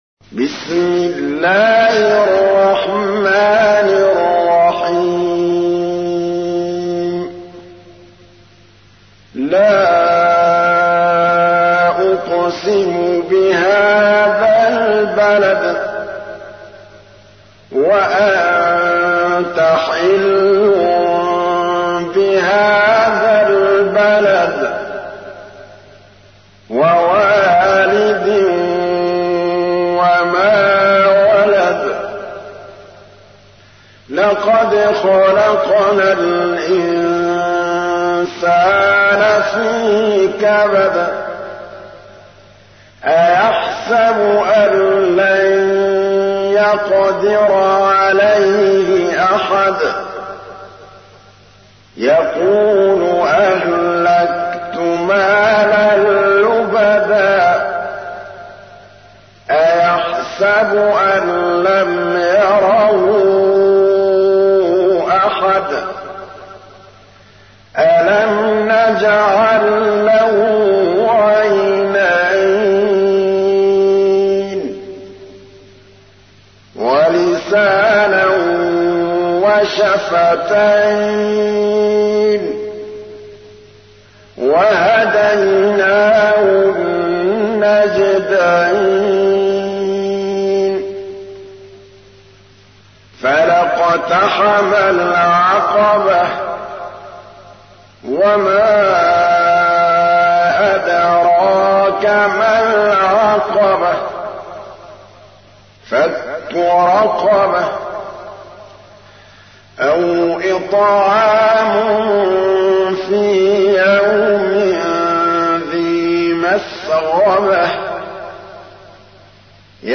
تحميل : 90. سورة البلد / القارئ محمود الطبلاوي / القرآن الكريم / موقع يا حسين